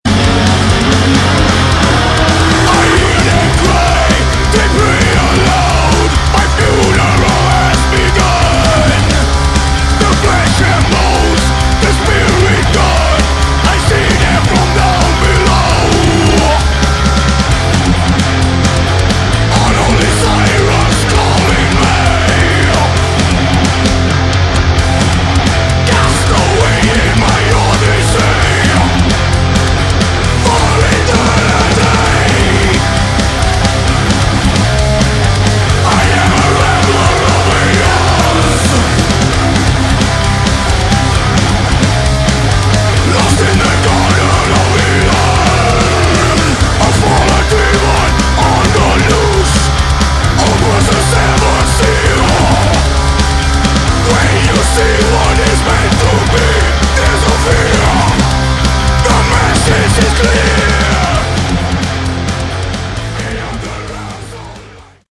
Category: Melodic Metal
vocals
guitar
bass
drums
This thrash.